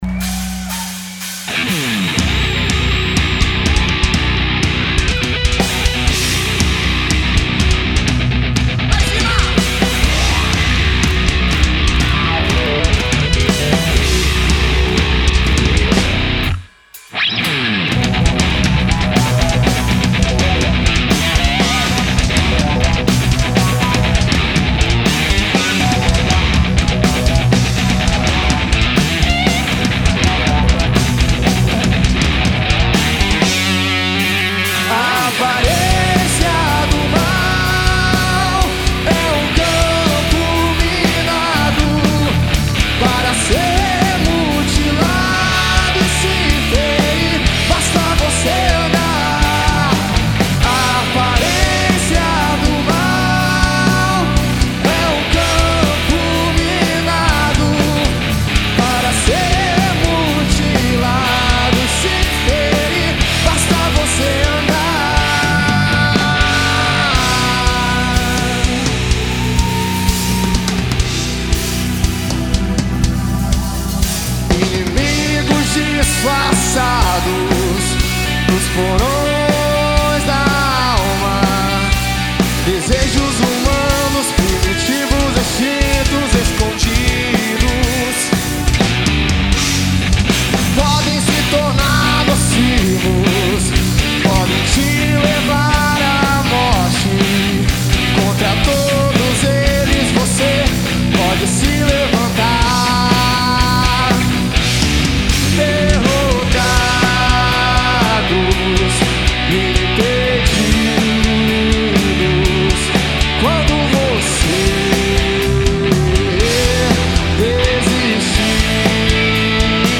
voz
bateria
baixo
teclados
guitarra